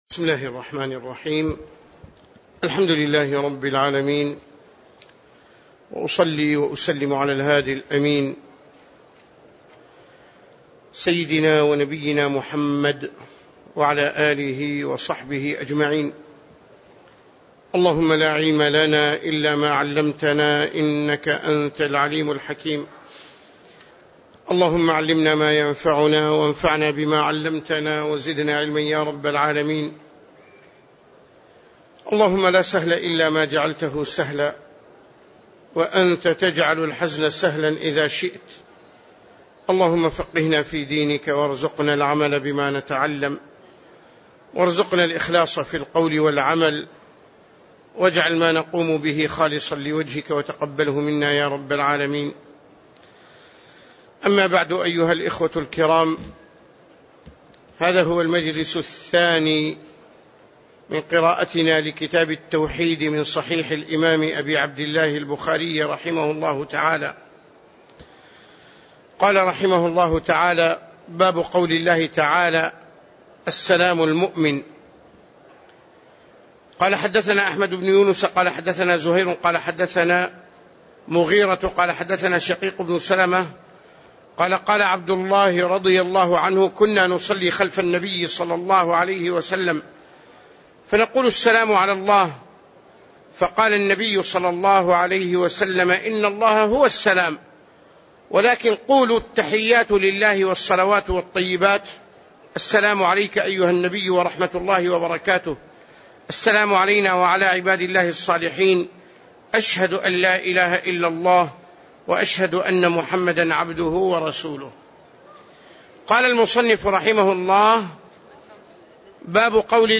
تاريخ النشر ١٨ محرم ١٤٣٩ هـ المكان: المسجد النبوي الشيخ